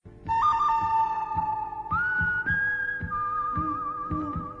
Play GBU Short Whistle - SoundBoardGuy
gbu-short-whistle.mp3